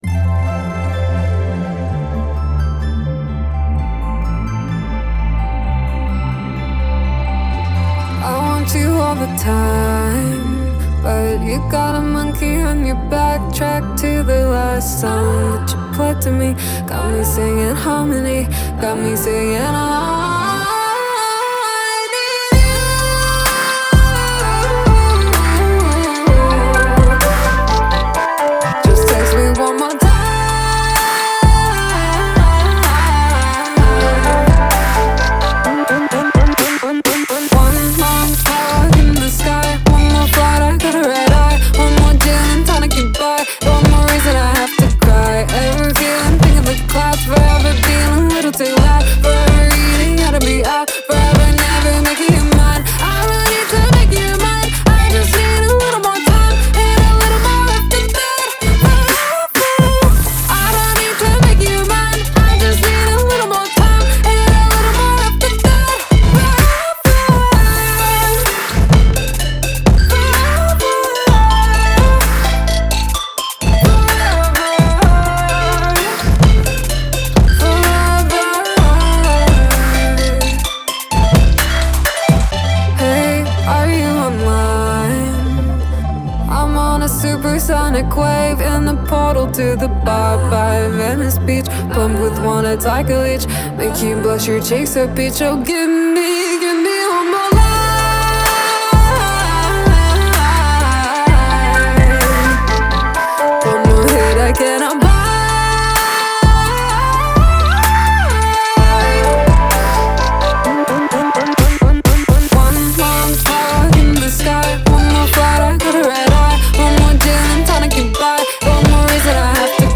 BPM128-128
Audio QualityPerfect (High Quality)
Hyperpop / Trap song for StepMania, ITGmania, Project Outfox
Full Length Song (not arcade length cut)